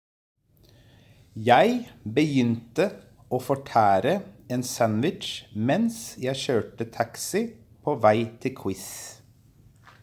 Well… Because as always I was curious about their lifestyles here in Norway, so I asked them a few questions and of course I recorded their voices reading that pangram to discover how they pronounce the Norwegian sounds!